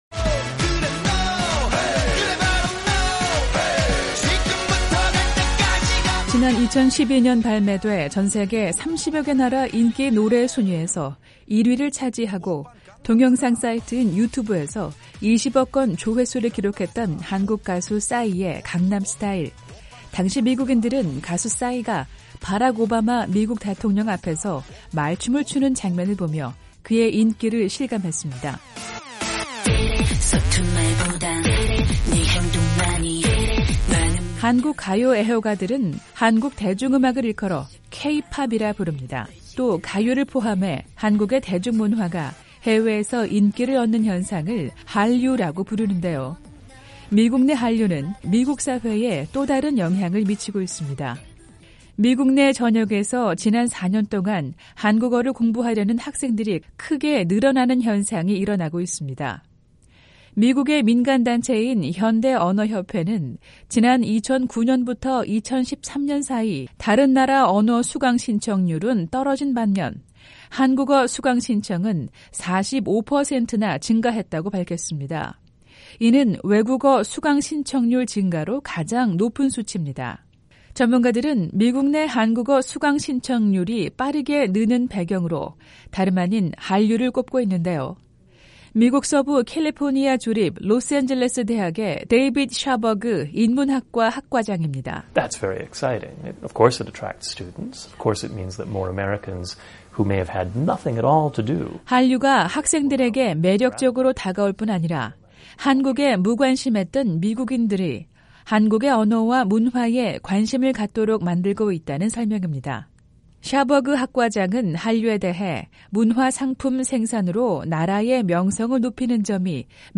매주 화요일 화제성 뉴스를 전해 드리는 `뉴스 투데이 풍경'입니다. 미국 대학에서 한국어의 인기가 점차 높아지고 있는 것으로 나타났습니다.